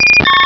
pokeemerald / sound / direct_sound_samples / cries / butterfree.aif
-Replaced the Gen. 1 to 3 cries with BW2 rips.